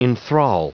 Prononciation du mot enthral en anglais (fichier audio)
Prononciation du mot : enthral